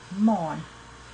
moon1 [moon]